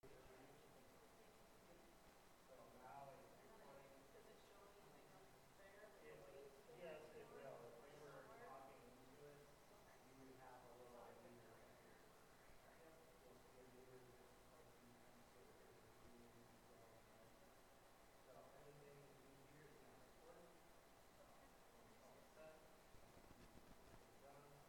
Sermons by First Baptist Church of Newberry Michigan